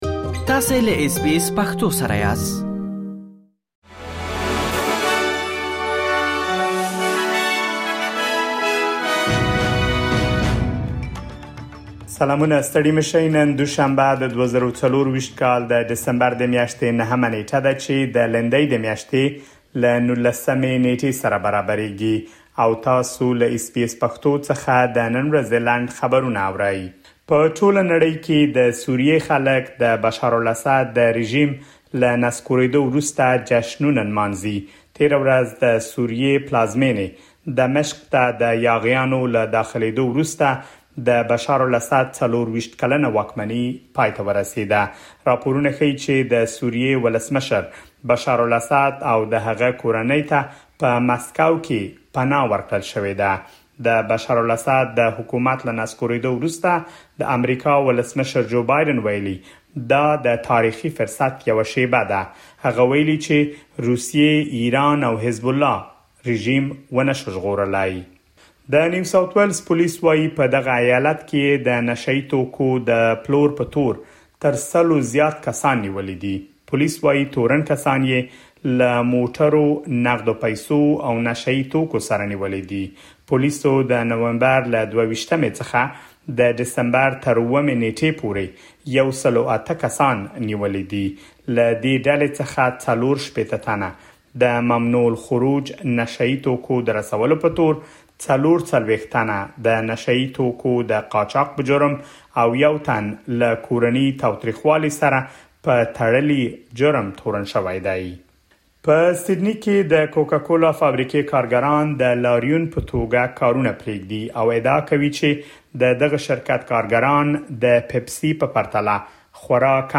د اس بي اس پښتو د نن ورځې لنډ خبرونه |۹ ډسمبر ۲۰۲۴